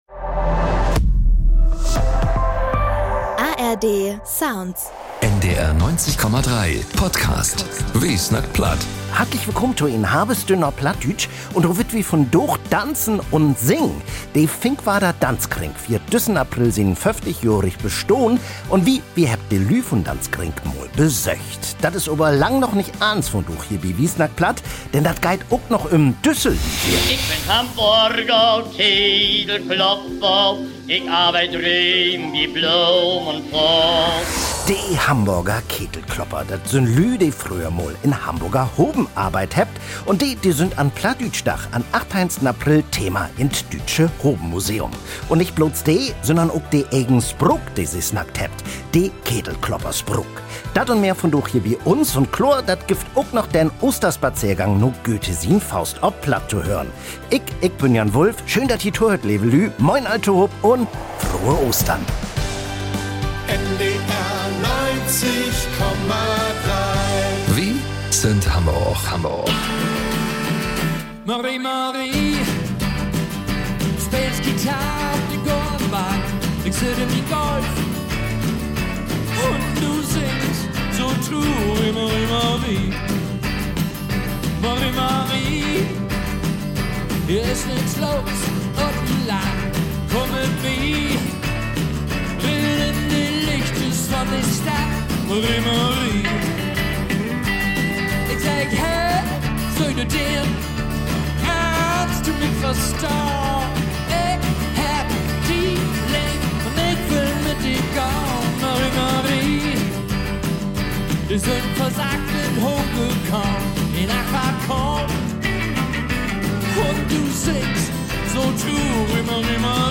Wi hebbt de Lüüd vun’n Danzkring mal bi een vun jümehr Proven besöcht.